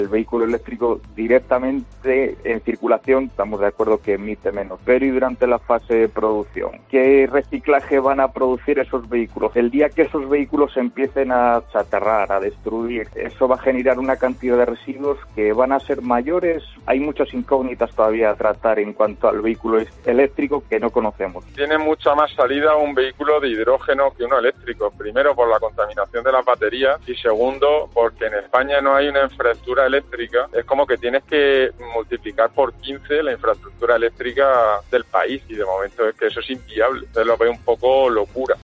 mecánicos